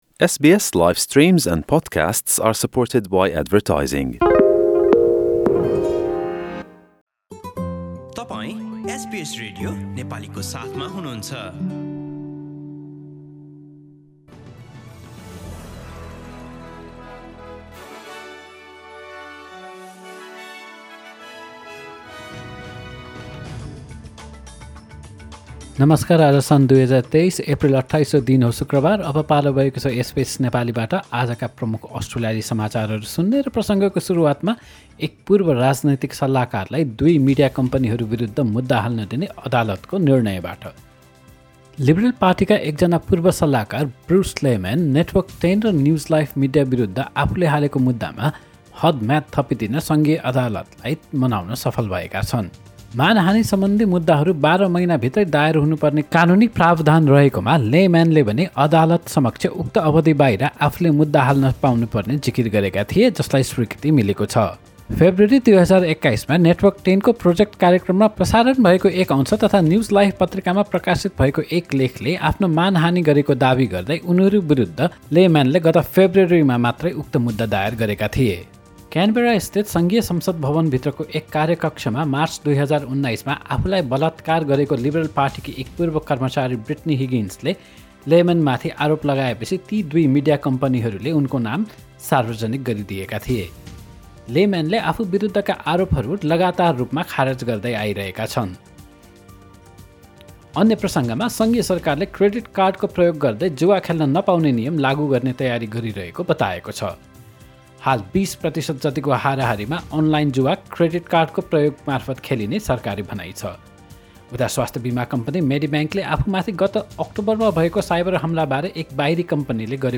एसबीएस नेपाली प्रमुख अस्ट्रेलियाली समाचार : शुक्रवार, २८ एप्रिल २०२३